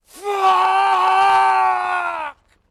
V souvislosti s rolí Roberta Redforda v novém Captainu Americovi jsem si vzpomněl na jeho nedávnou nejlepší roli a nejlepší hlášku ve filmu Vše je ztraceno.
All_is_Lost_FUUUCK.mp3